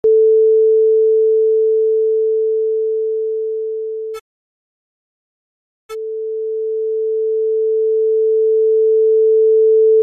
A noise gate is an expander with a ratio of 10:1 or higher.
Noise gated signal
When the noise gate is applied, the signal cuts off when its level drops below the threshold.